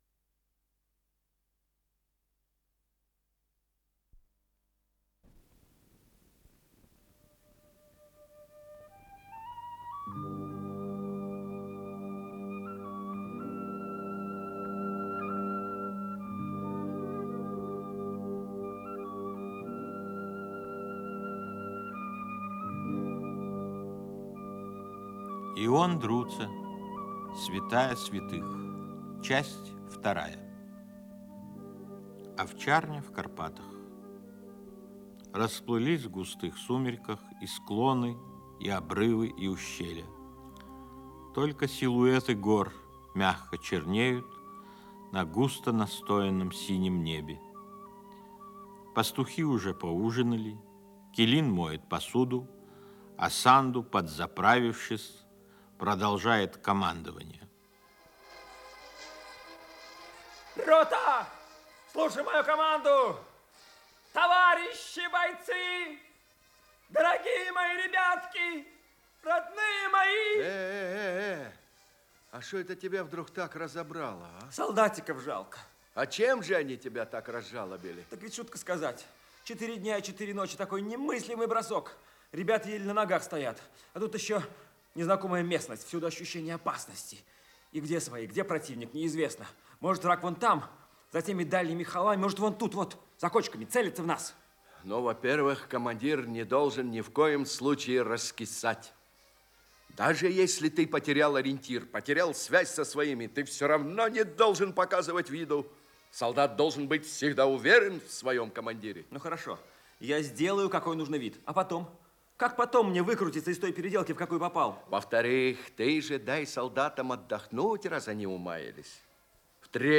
Название передачи Святая святых Подзаголовок Радиокомпозиция по спектаклю Ленинградского драматическго театра им. Комиссаржевской